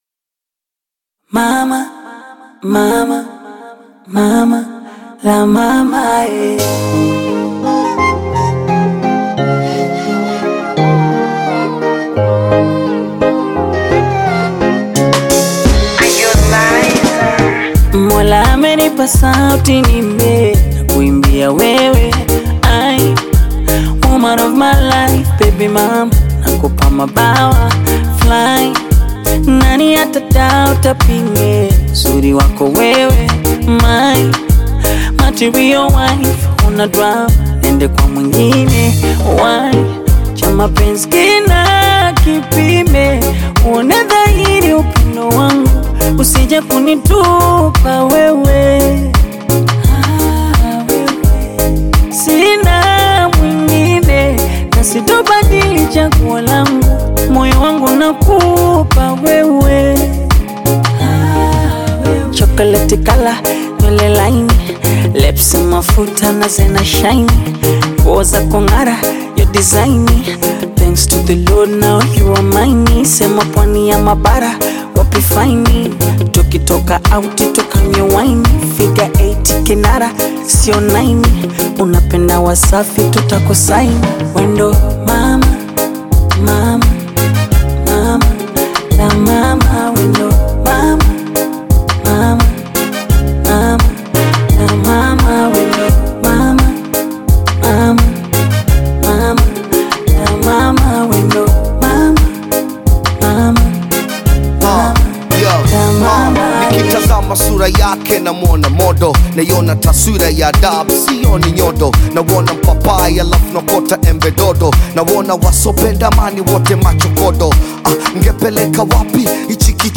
romantic Bongo Flava/Afro-Pop anthem
Genre: Bongo Flava